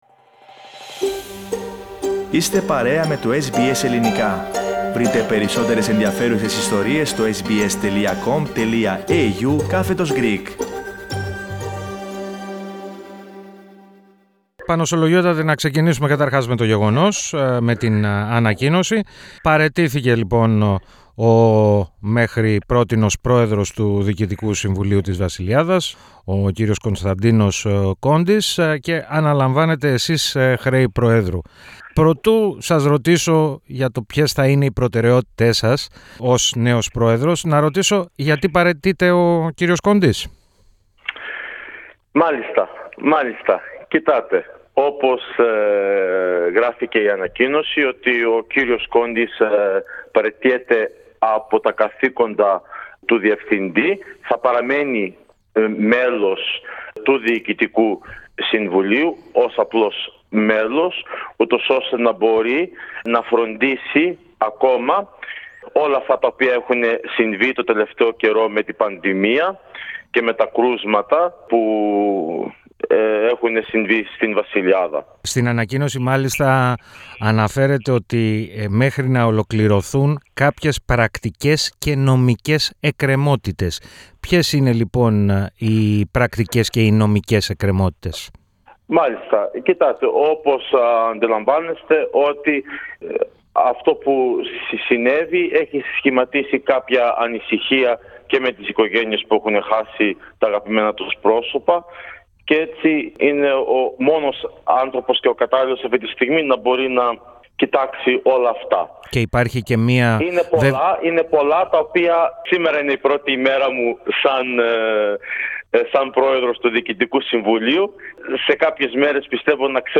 ο οποίος μίλησε στο Ελληνικό Πρόγραμμα της ραδιοφωνίας SBS. Μεταξύ άλλων, εξέφρασε συγγνώμη για τα όσα έχουν συμβεί στη Βασιλειάδα.